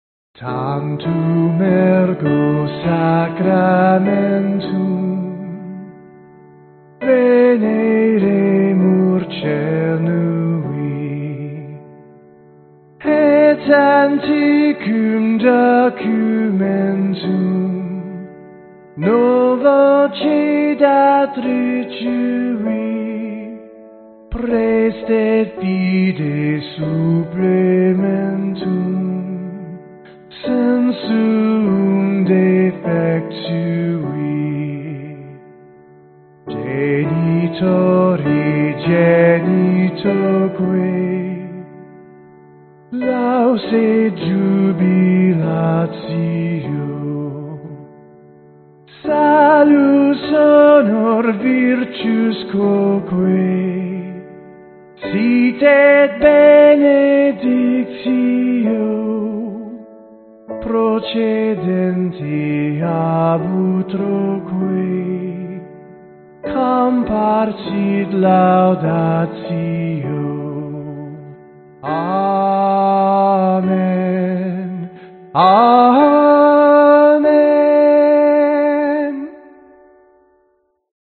标签： calm stereo hymn
声道立体声